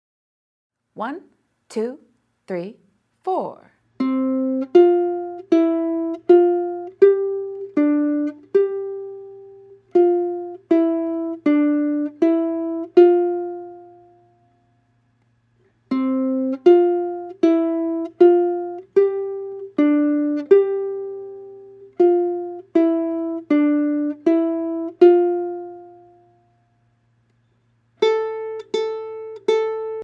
Voicing: Ukulele